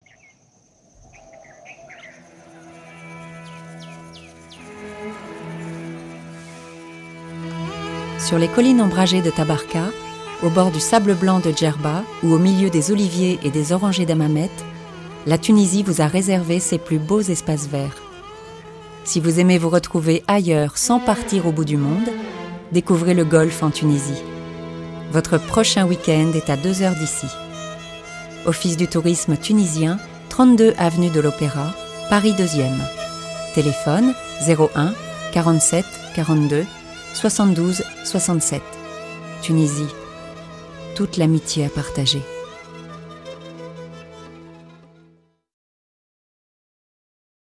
Pub radio. "Tunisie"
Comédienne